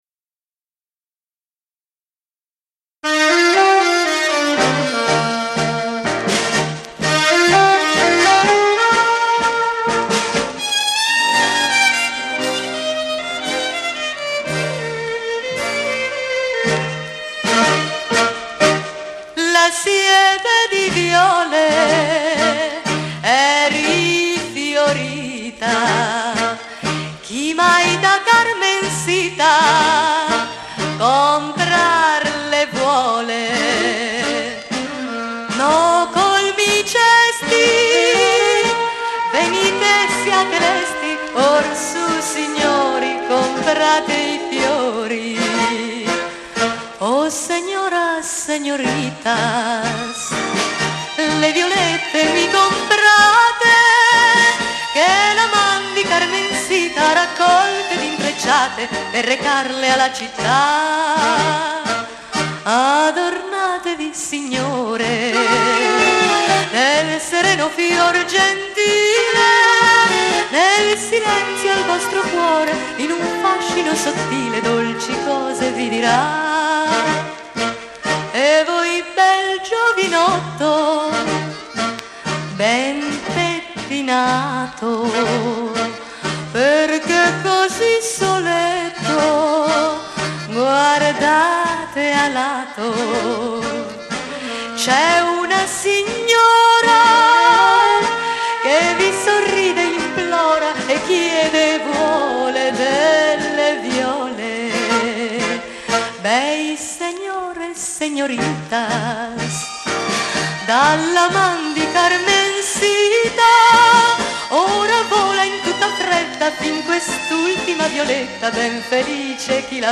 TANGO BOLERO